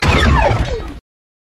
Futuristic Weapons Sound Effects – Laser-assault-weapon – Free Music Download For Creators
Futuristic_Weapons_Sound_Effects_-_laser-assault-weapon.mp3